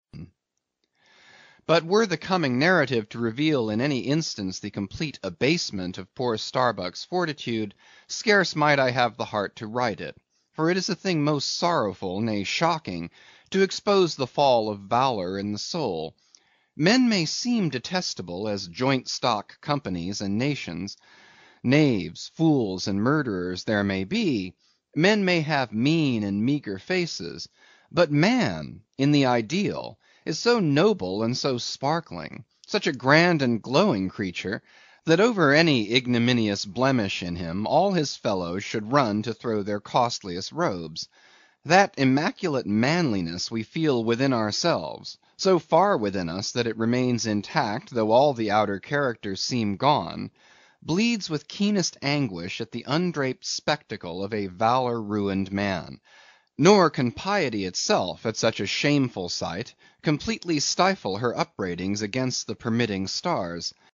英语听书《白鲸记》第147期 听力文件下载—在线英语听力室